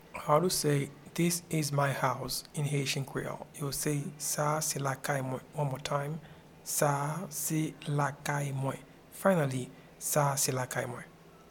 Pronunciation and Transcript:
This-is-my-house-in-Haitian-Creole-Sa-se-lakay-mwen.mp3